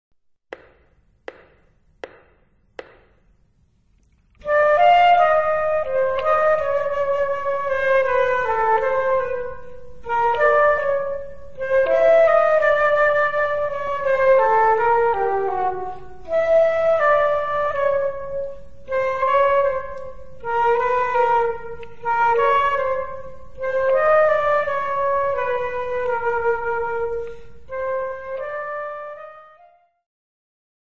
Instrumental Ensembles Flute
A challenge in close harmony playing.
Flute Duet